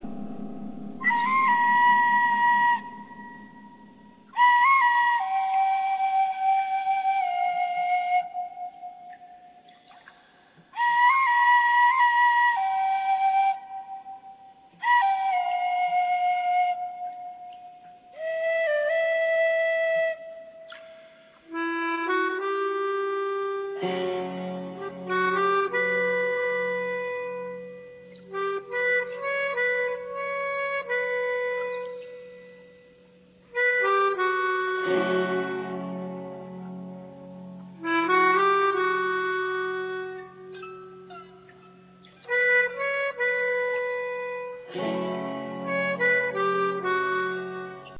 Woodwinds, tribal drums and an underlying ethereal sound
Loud, heartfelt and slightly wicked
tribal-ethereal-instrumental-rock-and-roll